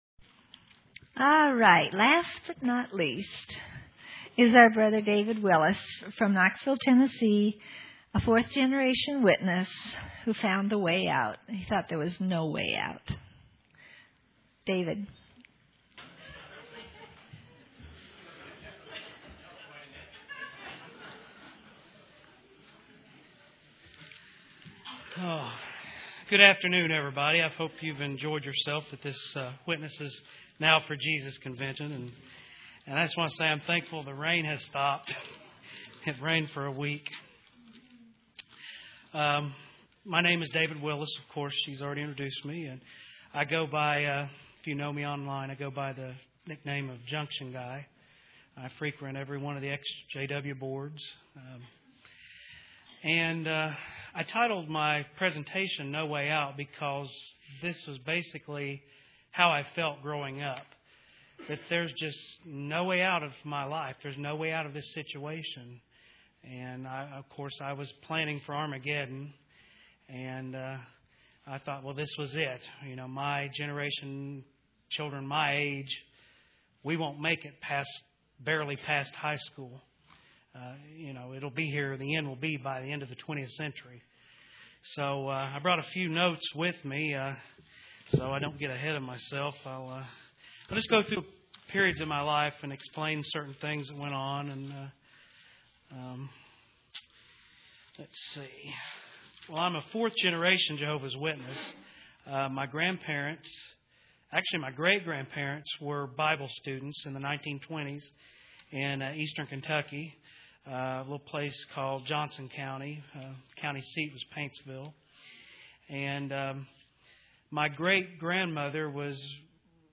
Listen to Testimonies of Ex-Jehovah's Witnesses